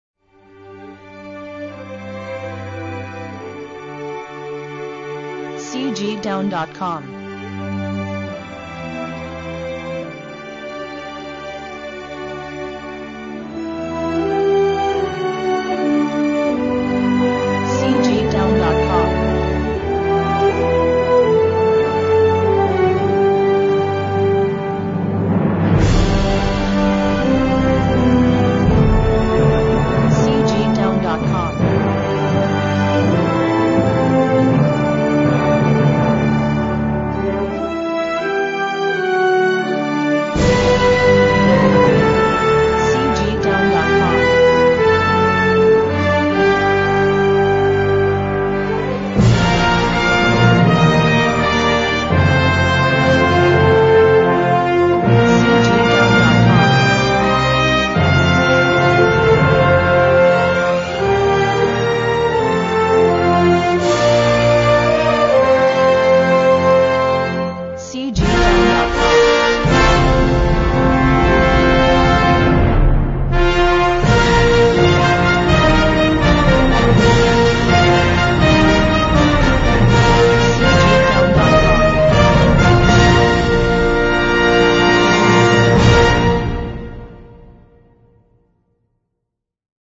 1970-01-01 辉煌感动